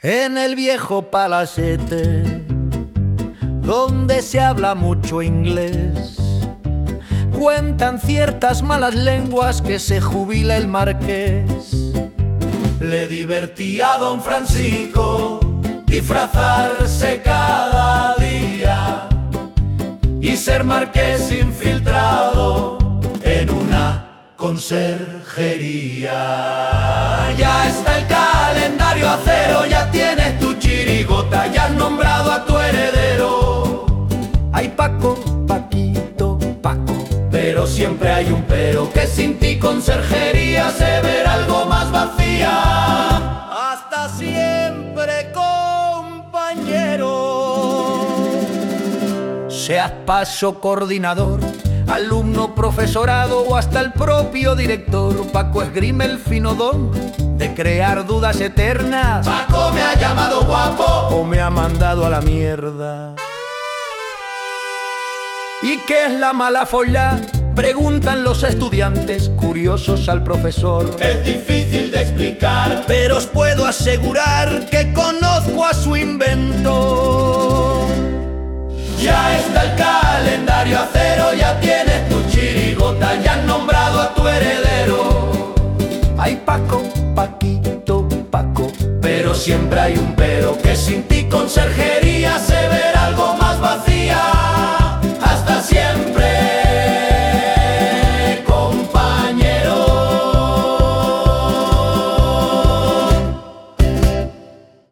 CANCIONES